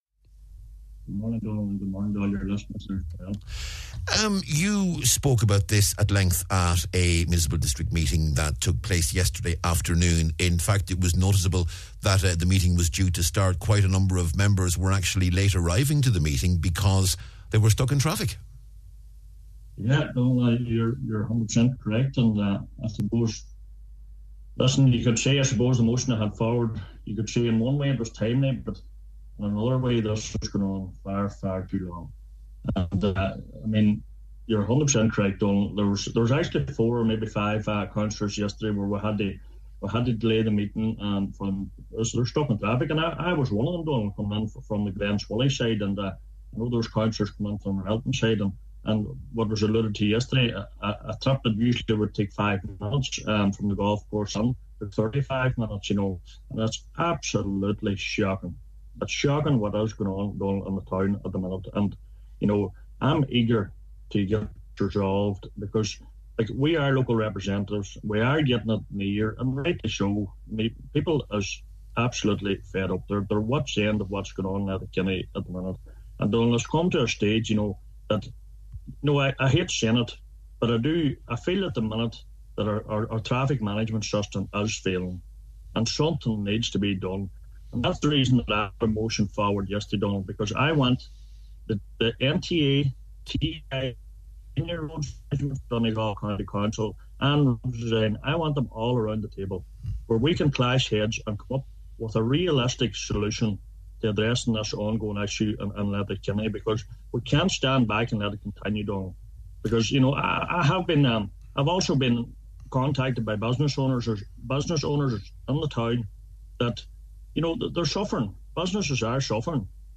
Cathaoirleach of the Letterkenny-Milford Municipal district is calling for TII officials to visit Letterkenny. It follows his beliefs that the current traffic management system in the town is failing. Speaking on this mornings Nine ’til Noon Show, Cllr. Donal Mandy Kelly says there are consequences to the failure, particularly for local businesses: